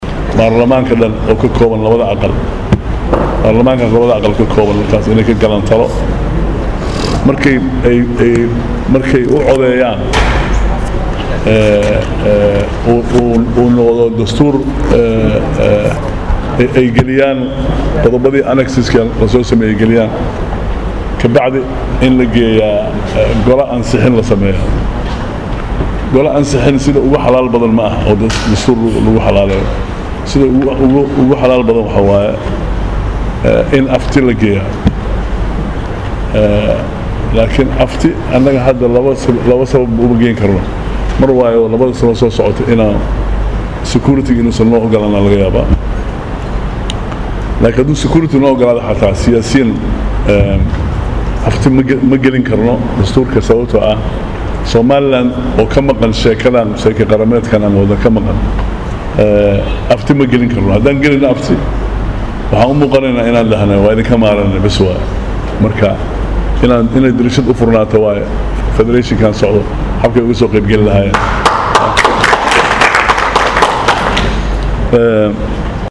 DHAGAYSO COD: Wasiirka Wasaaradda Dastuurka DFS oo sheegay in aan afti loo qaadi karin Dastuurka inta ay Soomaaliland…. | Goobsan Media Inc